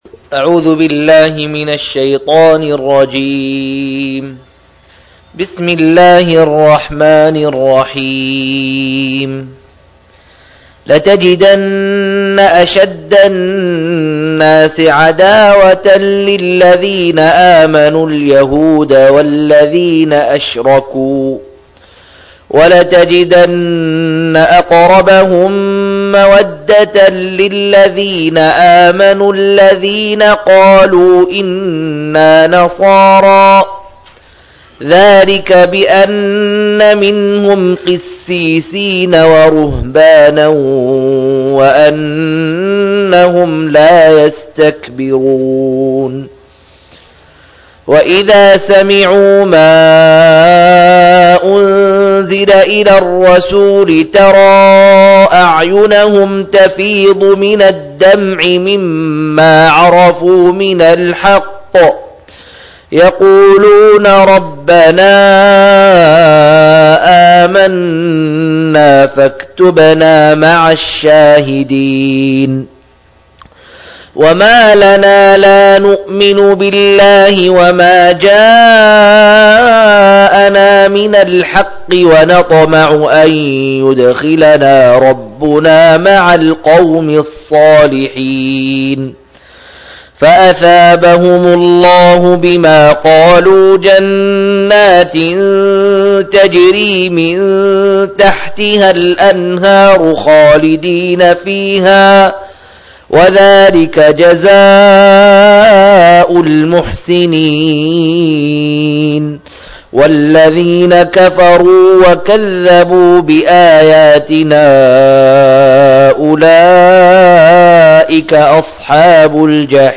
120- عمدة التفسير عن الحافظ ابن كثير رحمه الله للعلامة أحمد شاكر رحمه الله – قراءة وتعليق –